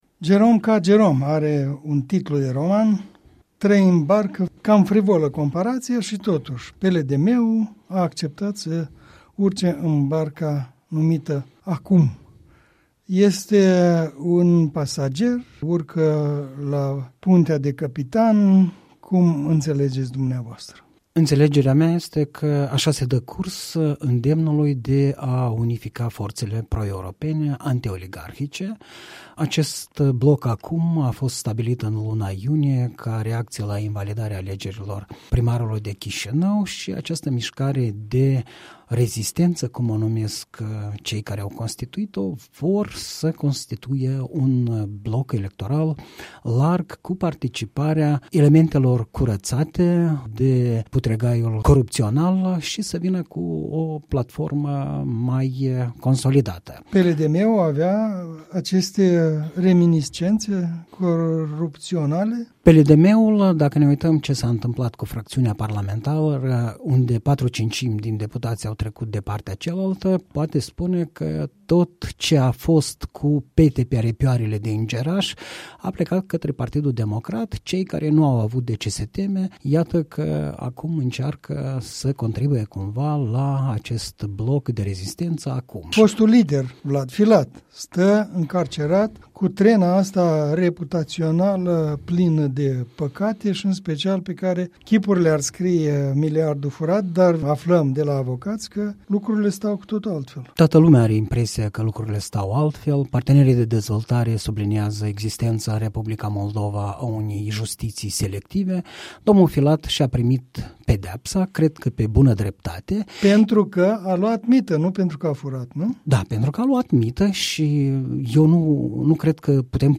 Un punct de vedere săptămânal în dialog.